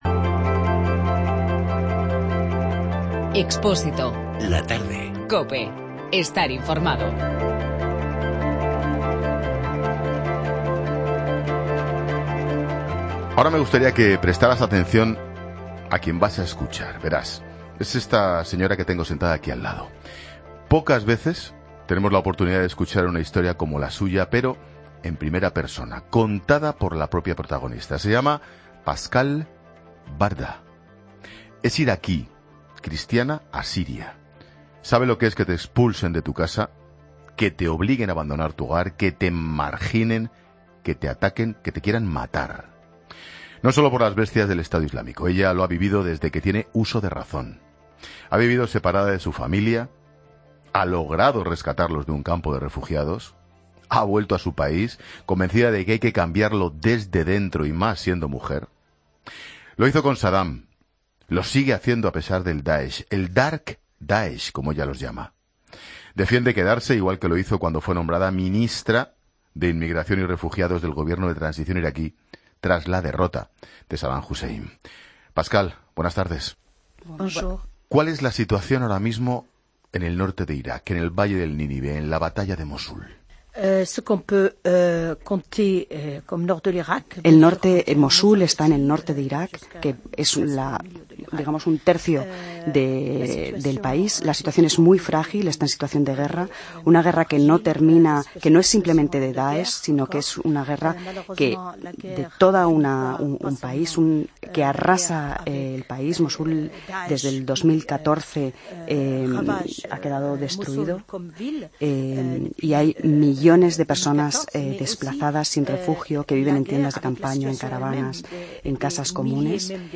AUDIO: Pascale Warda es una cristiana asiria y exministra iraquí de Inmigración.